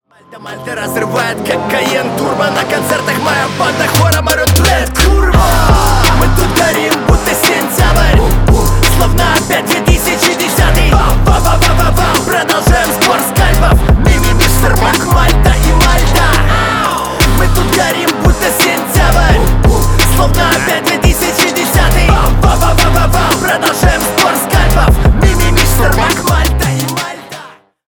бесплатный рингтон в виде самого яркого фрагмента из песни
Рэп и Хип Хоп